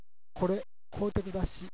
能登弁とはいえ私が能登に住んでいたころ使っていた方言です。
地域によってはまったく違った言い方の場合もあります。